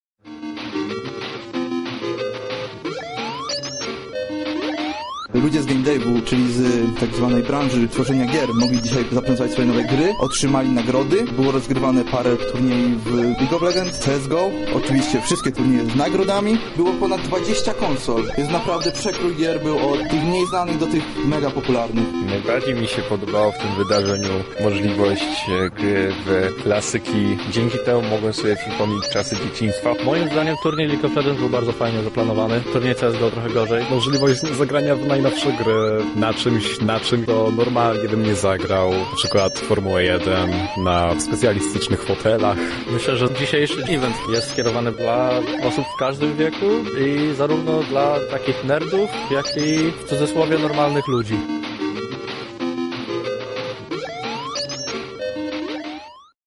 W miniony weekend hala EXPO w Krakowie zamieniła się w raj dla fanów gier video.
Na miejscu byli nasi reporterzy, którzy pytali uczestników o wrażenia: